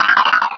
Cri de Balbuto dans Pokémon Rubis et Saphir.